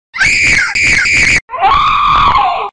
Funny High Pitched Scream